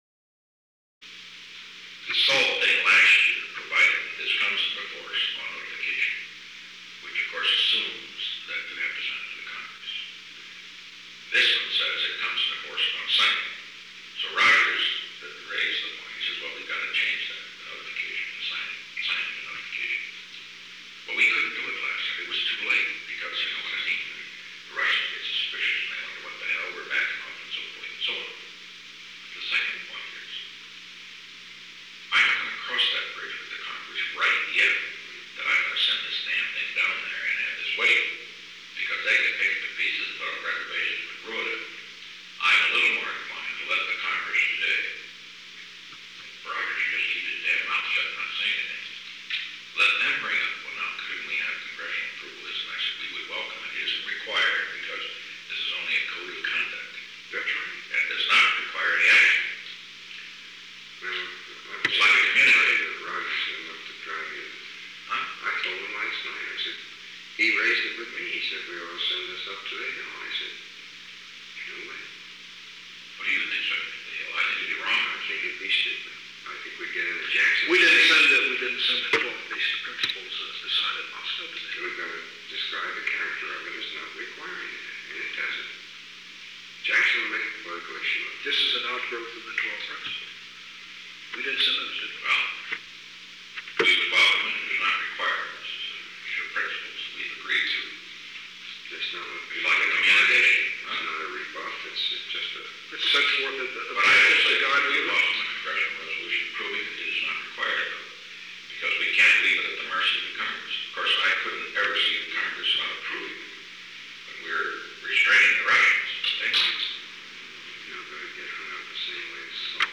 Conversation No. 946-6 Date: June 22, 1973 Time: 9:45 am - 10:31 am Location: Oval Office The President met with Alexander M. Haig, Jr. and Ronald L. Ziegler.
Secret White House Tapes